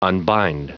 Prononciation du mot unbind en anglais (fichier audio)
Prononciation du mot : unbind